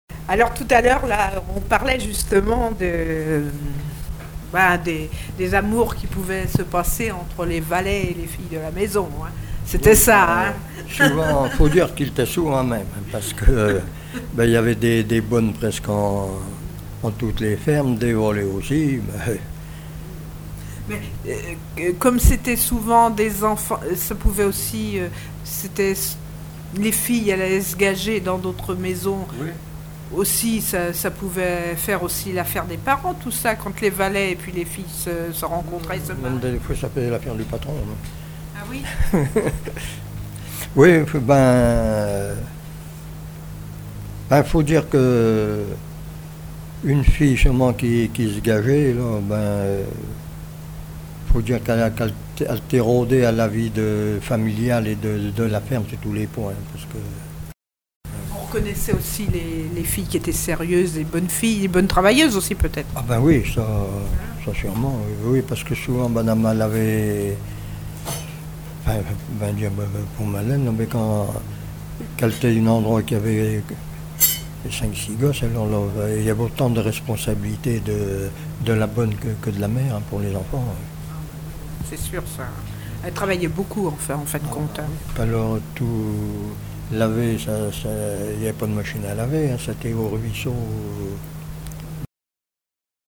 Témoignages de vie
Catégorie Témoignage